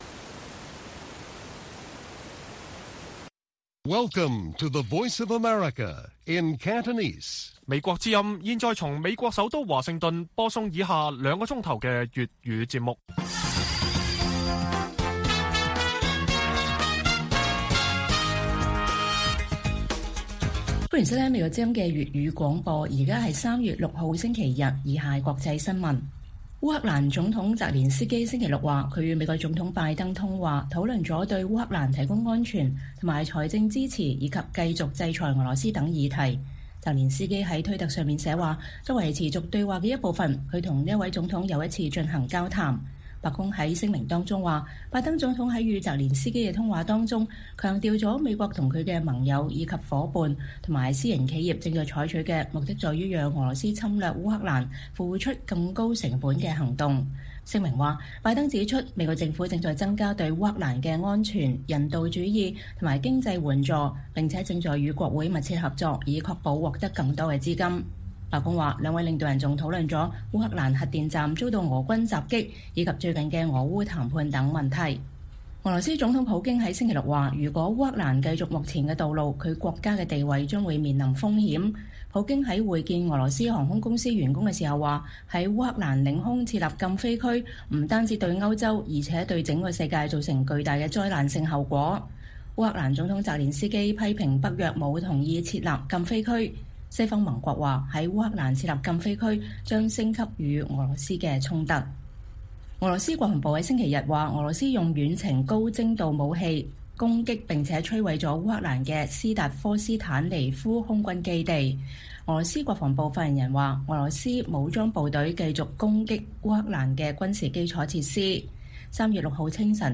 粵語新聞 晚上9-10點: 俄羅斯入侵烏克蘭第11天 烏克蘭難民人數近150萬